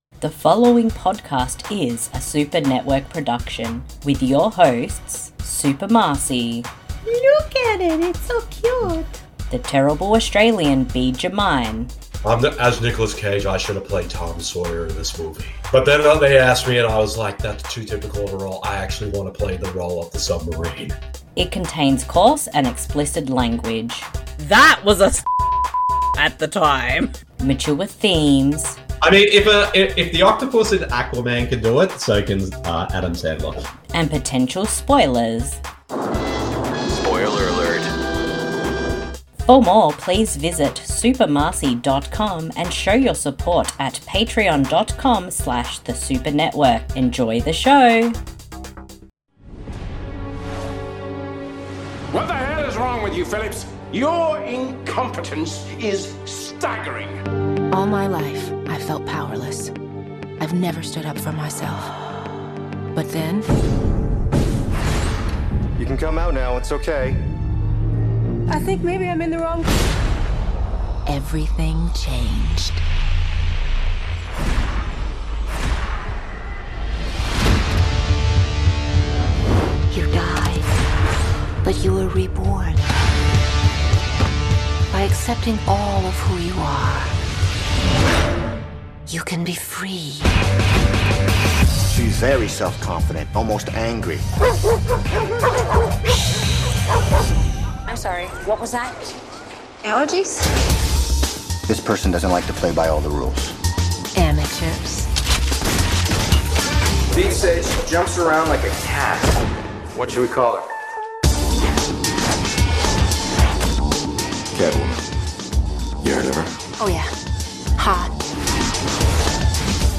You simply need to grab a copy of the film, and sync up the podcast audio with the film. We will tell you when to press start, it is that easy!